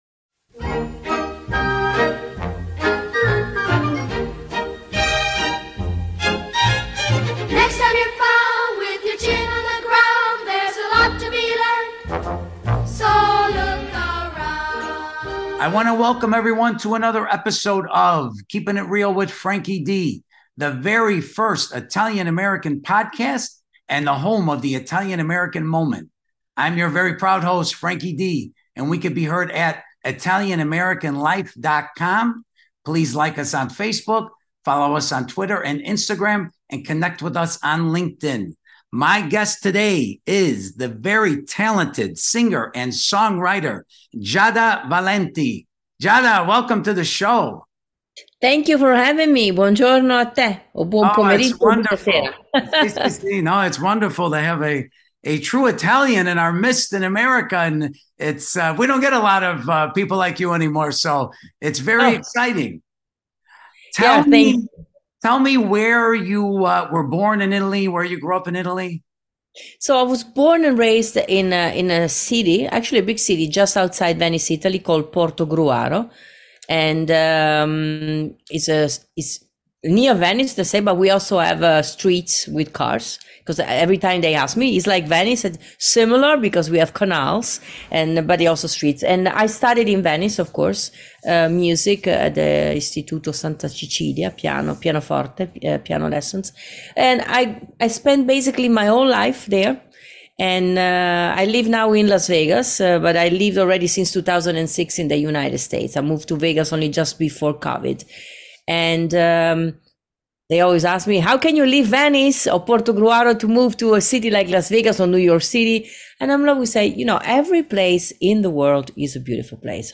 Interview with the very talented